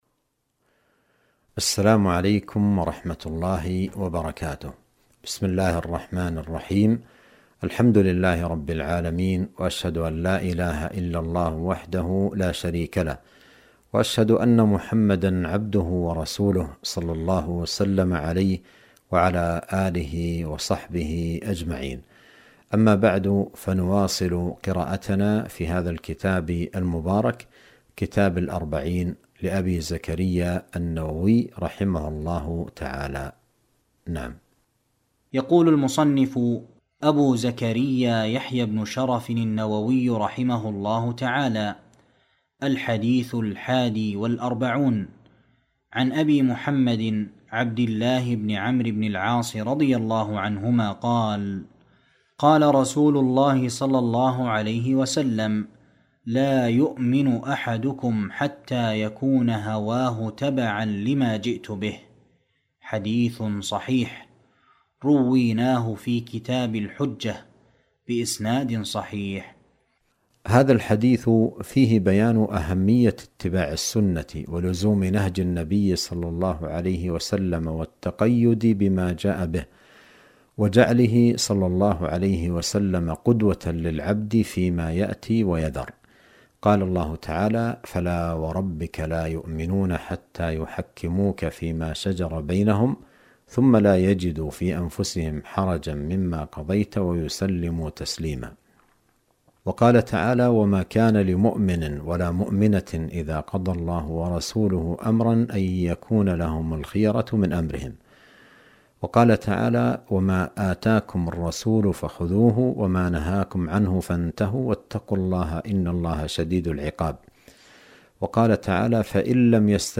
درس في قناة السنة النبوية بالمدينة النبوية